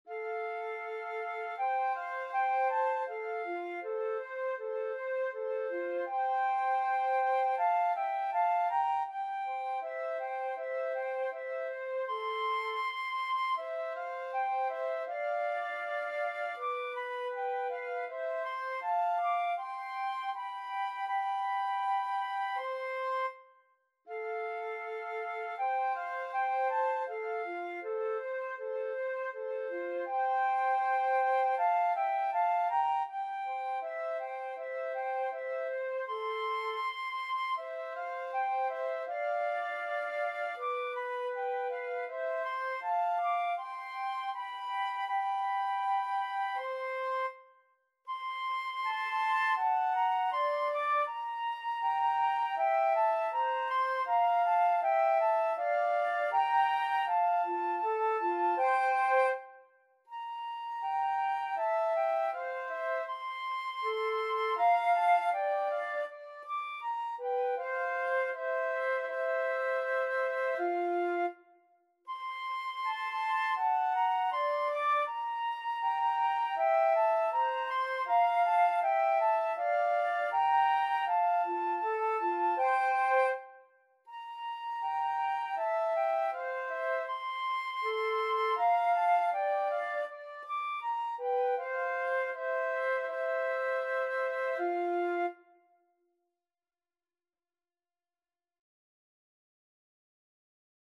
2/2 (View more 2/2 Music)
Flute Duet  (View more Easy Flute Duet Music)
Classical (View more Classical Flute Duet Music)